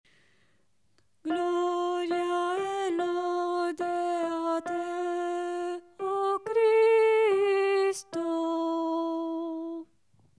CONTRALTISOPRANI
CDV-101-Gloria-e-Lode-a-Te-o-Cristo-CONTRALTI-3^-melodia.mp3